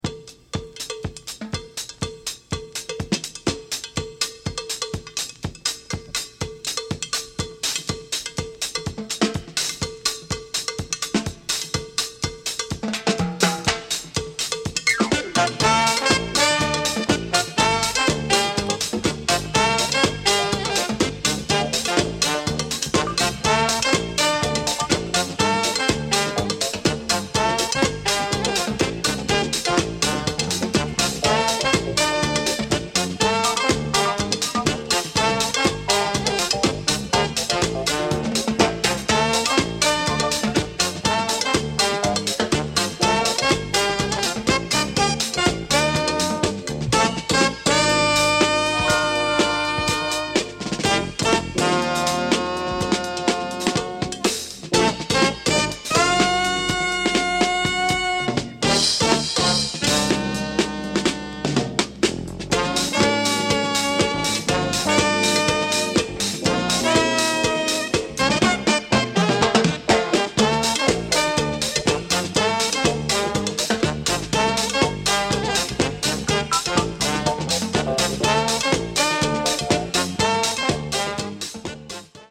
Rare UK 12″ of the Jazz-fusion monster from Japan!